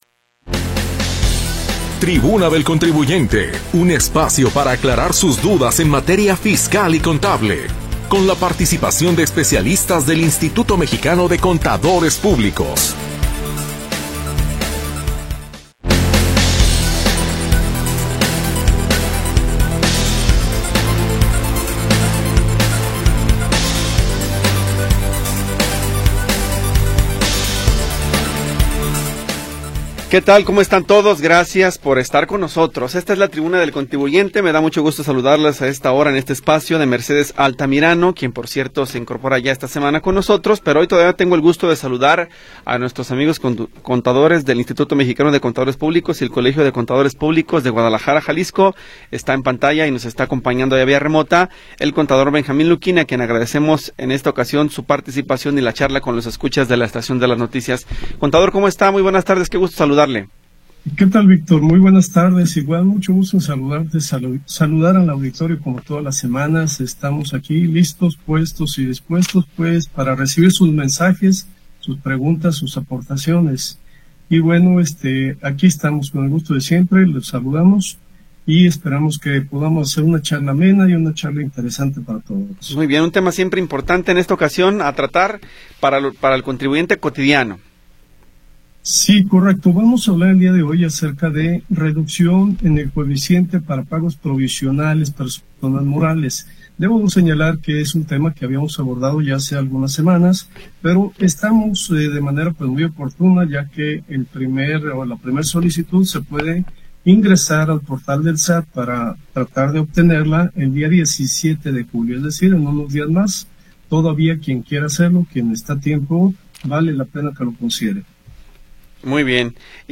Orientación legal y contable con la participación de especialistas del Instituto Mexicano de Contadores.
Programa transmitido el 7 de Julio de 2025.